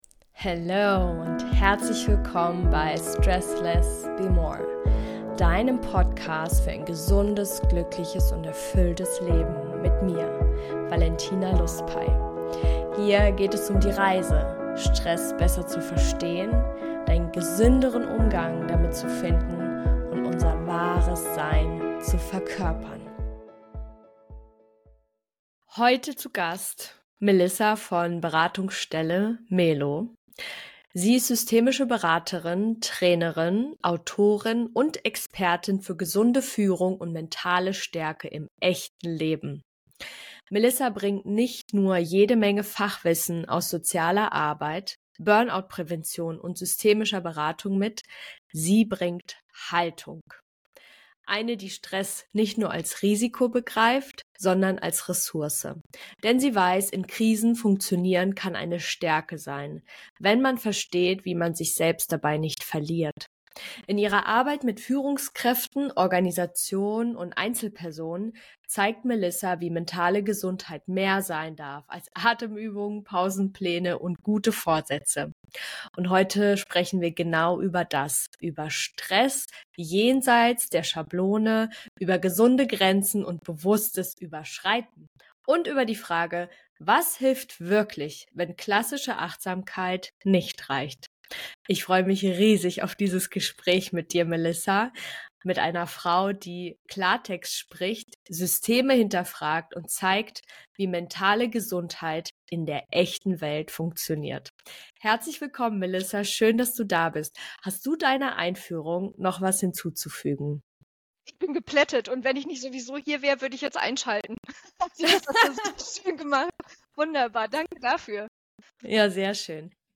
Freu dich auf ein Gespräch, das mit Schubladen bricht und neue Perspektiven aufmacht: Warum Stress keine Schwäche ist, sondern ein Signal für innere Bewegung Wie du mit Klarheit über deine Stressmuster in echte Selbstverantwortung kommst Was „Regeneration“ bedeutet, wenn du bewusst über deine Grenzen gehst Warum mentale Gesundheit mehr ist als Atemübungen und stille Pausen Wie du individuelle Tools findest, die wirklich zu deinem Leben passen Diese Folge ist für dich, wenn du keine Lust mehr auf pauschale Empfehlungen hast.